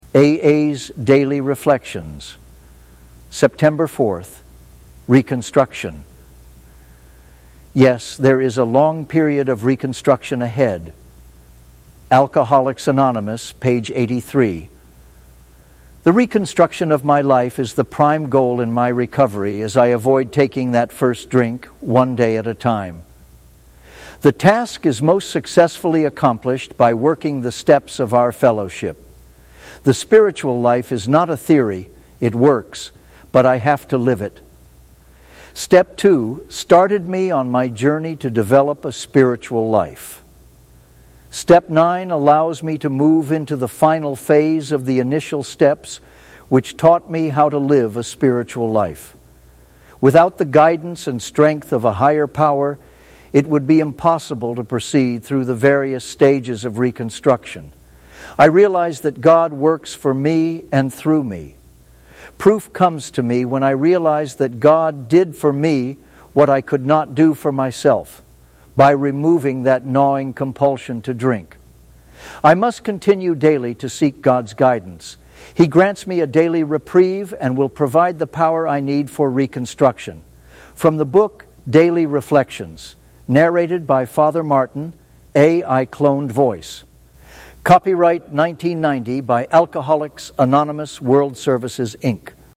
Daily Reflections